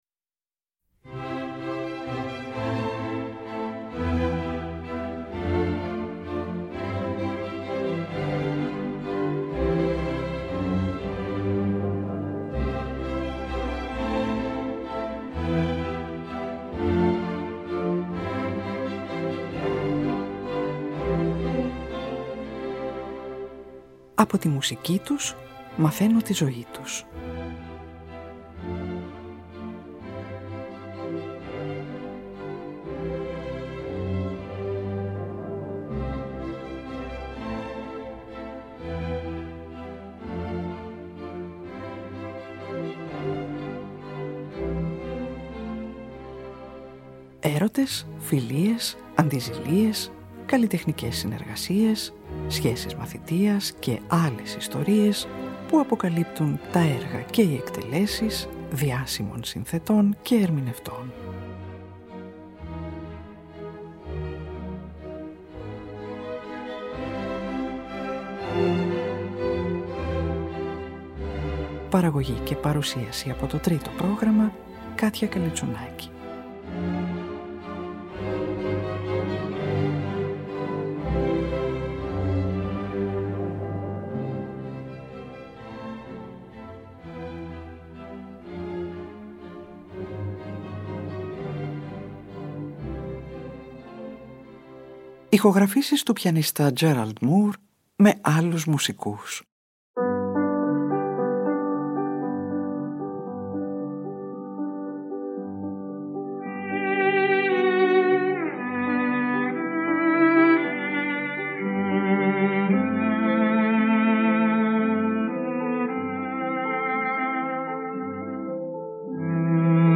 Ο Βρετανός πιανίστας
Από ηχογραφήσεις που έκανε στο Λονδίνο την περίοδο 1947-1969